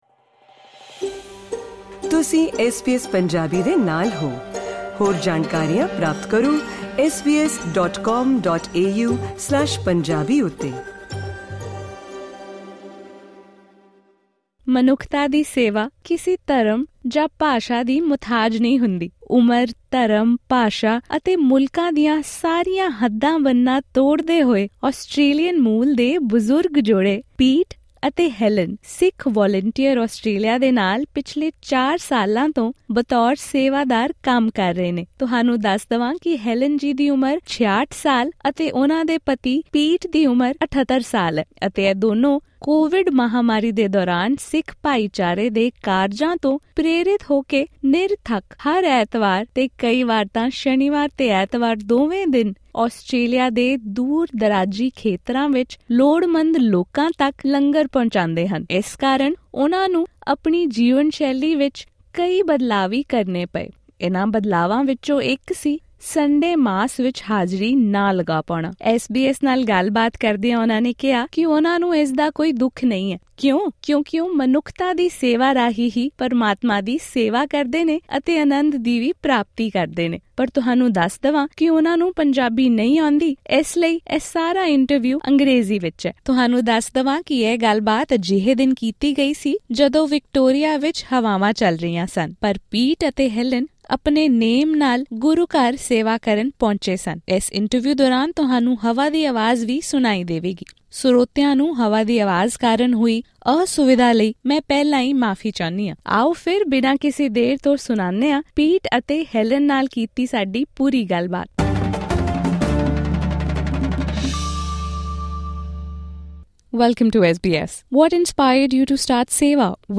ਇਹ ਖਾਸ ਗੱਲ-ਬਾਤ